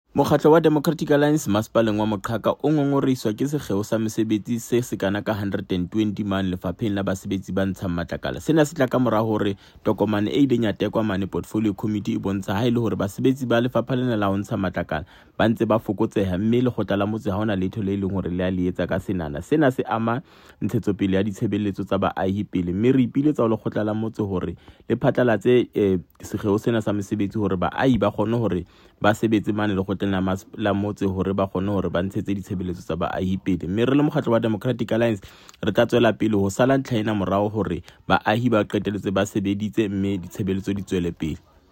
Sesotho soundbites by Cllr David Nzunga.